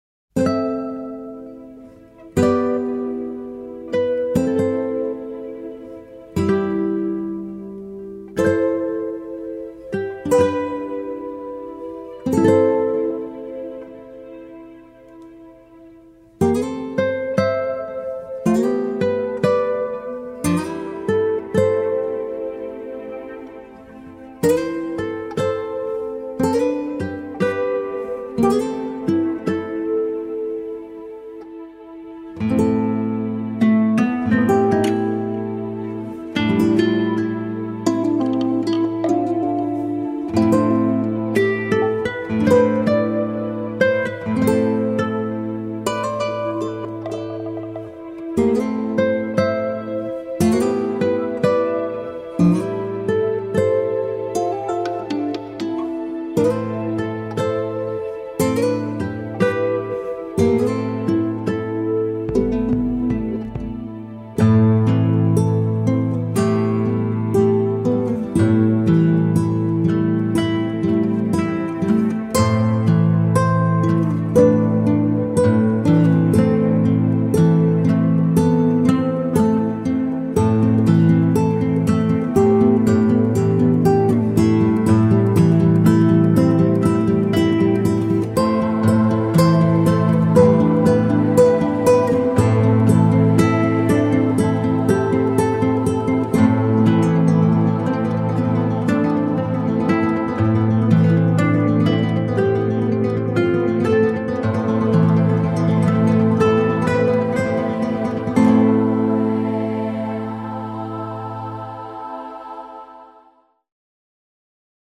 Largo [0-10] - - guitare - harpe - aerien - folk - melodieux